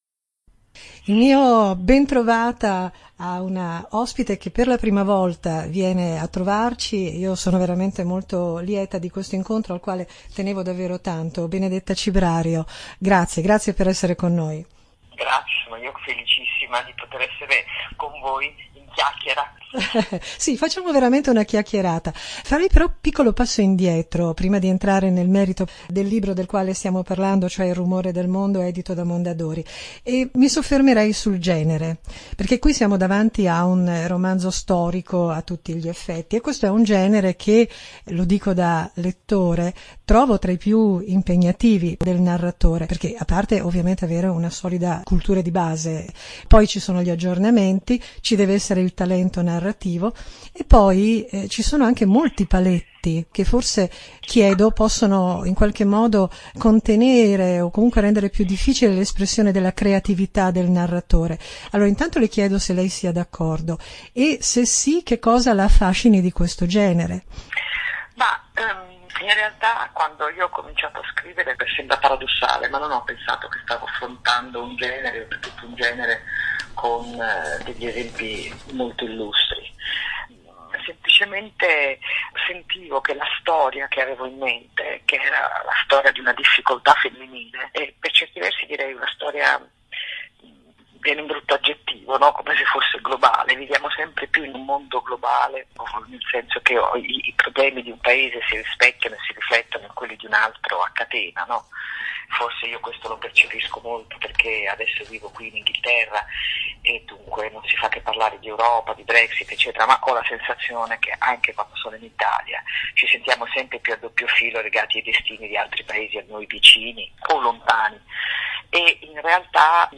“Il rumore del mondo”: chiacchierata con Benedetta Cibrario